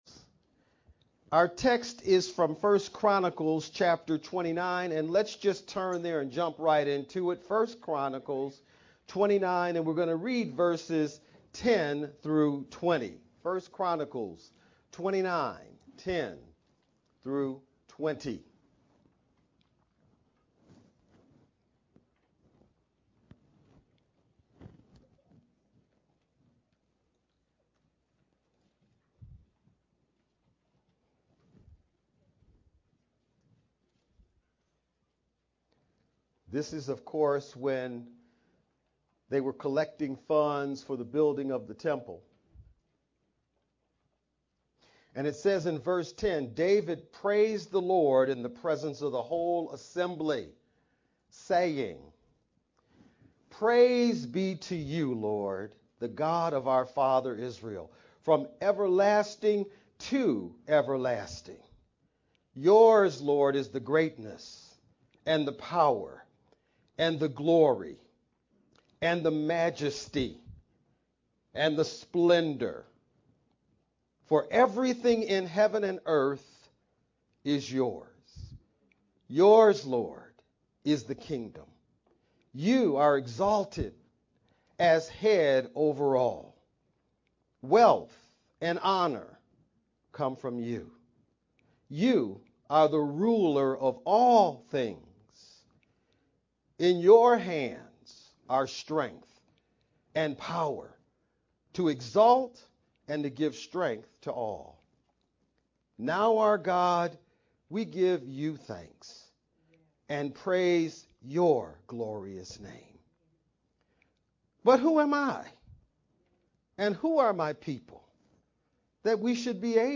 Feb-12-VBCC-sermon-only-Mp3-CD.mp3